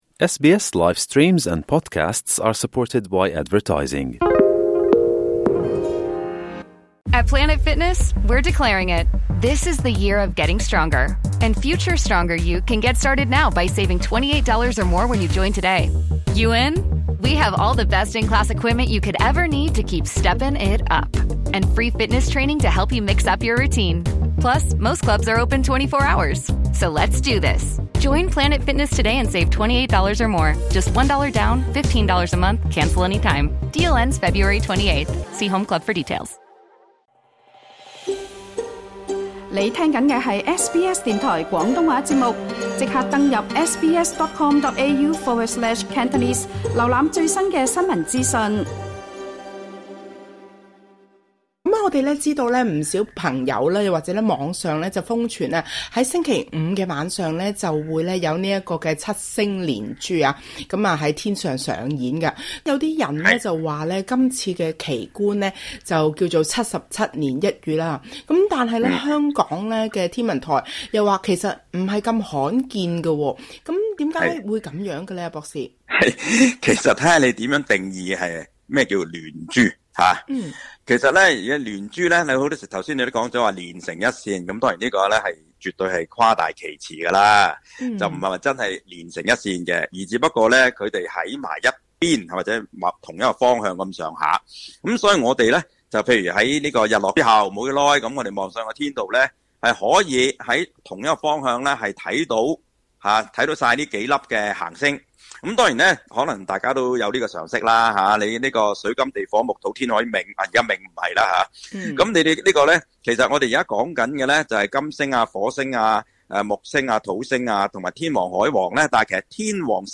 【專訪】